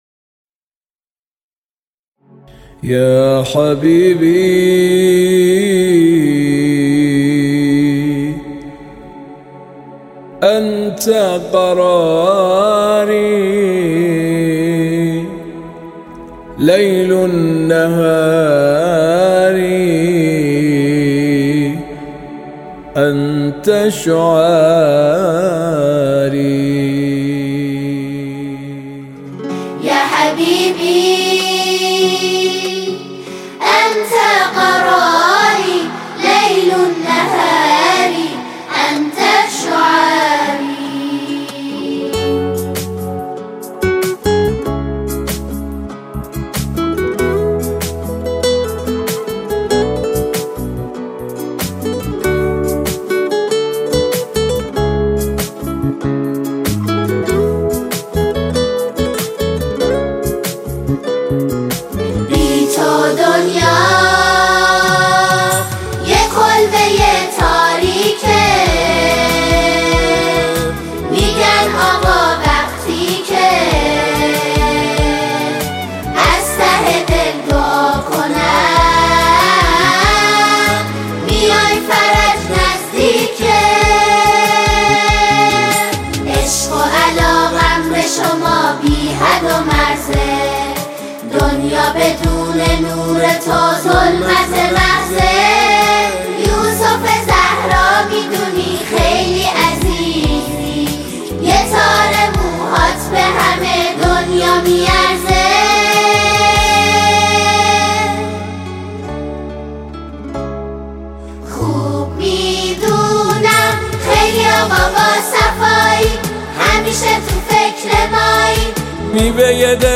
یک «هم‌سرایی ملی»
ژانر: سرود ، سرود مذهبی ، سرود مناسبتی